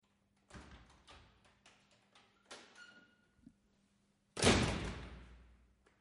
Descarga de Sonidos mp3 Gratis: puerta 4.
puerta_4.mp3